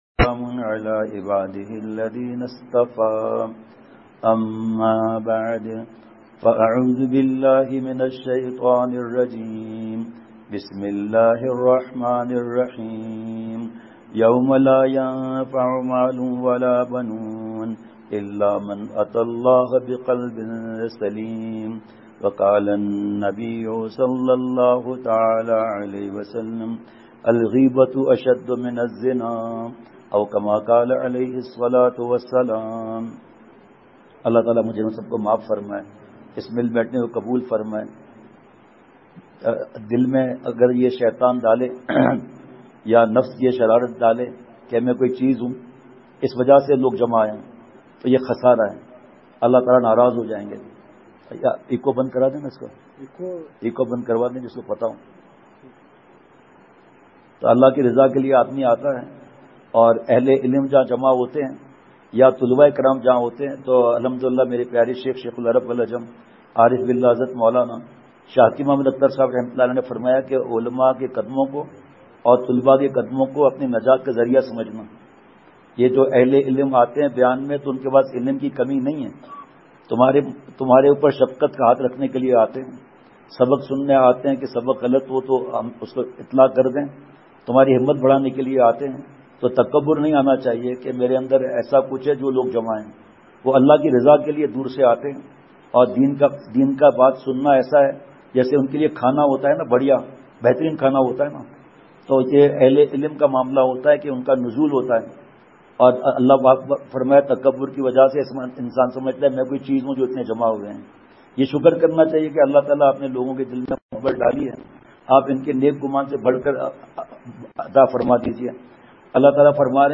پورا بیان بہت ہی جوش میں ہوا۔* *بیان کی مختصر تفصیل۔* *کونسا مال و اولاد کام آئے گا۔* *صحبت اہل اللہ کی اہمیت۔* *موبائل فون کی تباہ کاریاں۔* *نظر کی حفاظت کا مضمون۔* *وھو معکم این ما کنتم۔